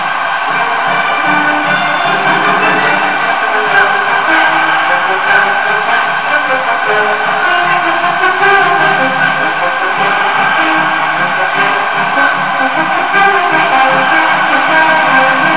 The college bands playing theme songs are a nice touch.